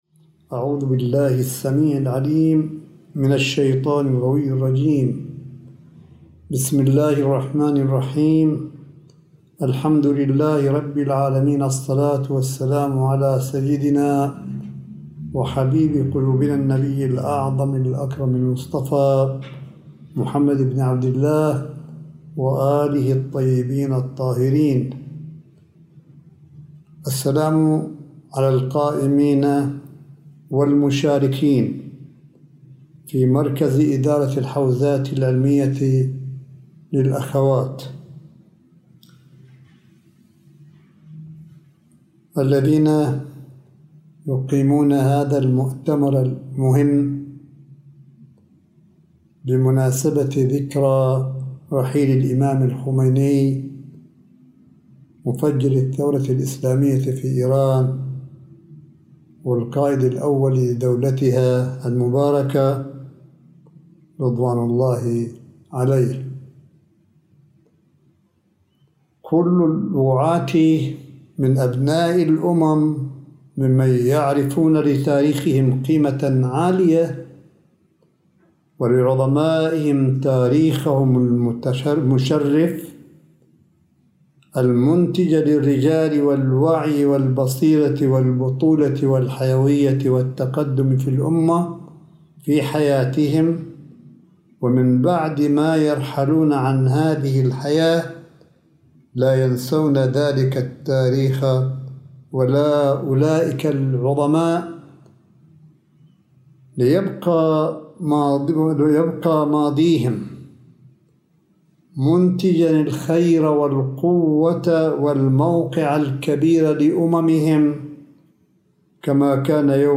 ملف صوتي لكلمة آية الله قاسم في ذكرى رحيل الإمام الخميني العظيم – 03 يونيو 2020 م